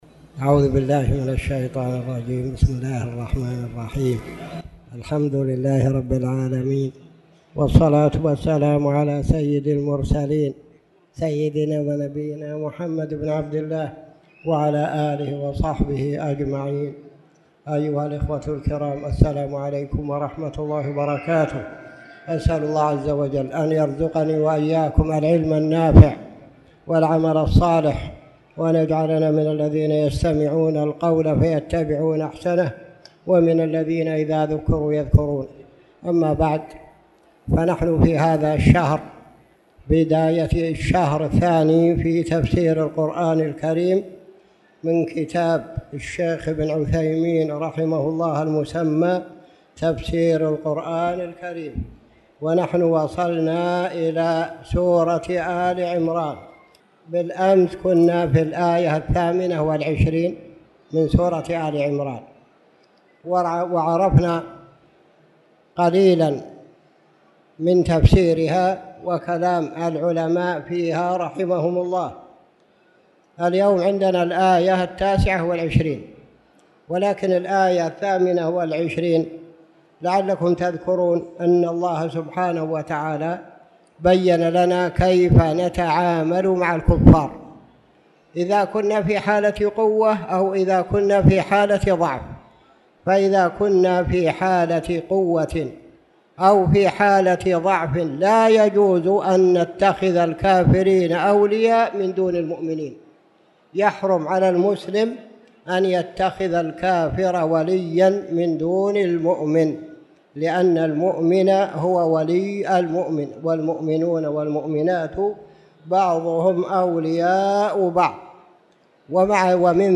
تاريخ النشر ٢ جمادى الأولى ١٤٣٨ هـ المكان: المسجد الحرام الشيخ